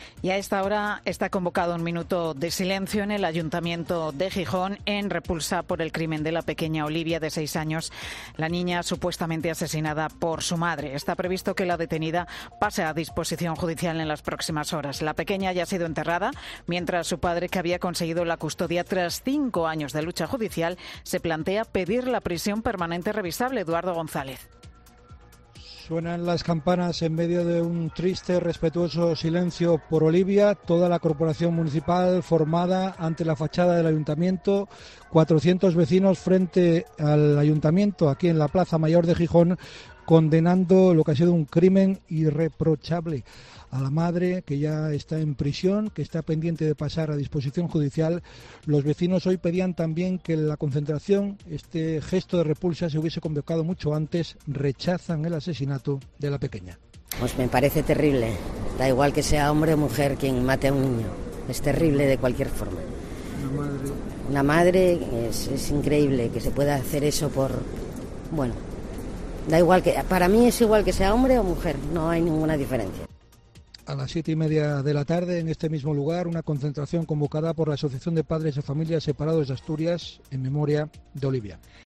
Minuto de silencio en repulsa por su asesinato. Crónica